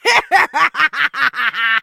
jackie_ulti_vo_02.ogg